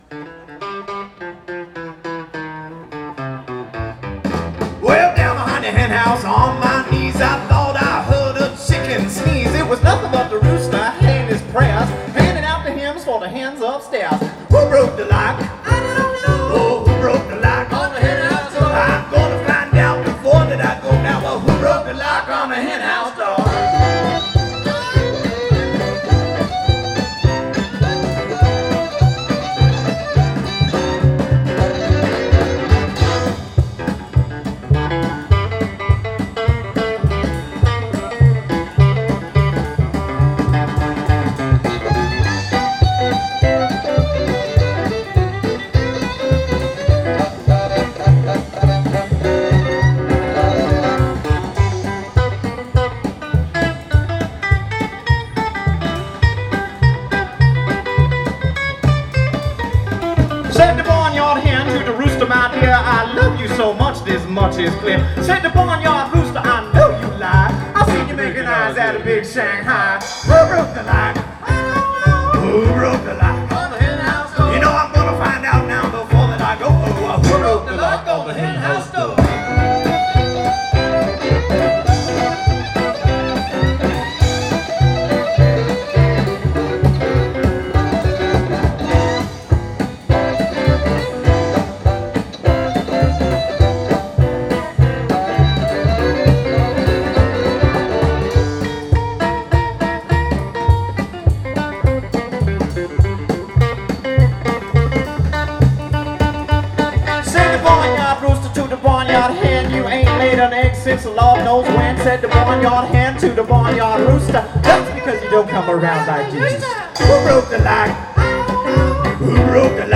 violin
old-time jazz, fiddle, and swing hokum tunes
washtub bass
energetic western swing style guitar
doghouse bass
Bele Chere, Asheville 2010